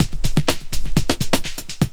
21 LOOP07 -R.wav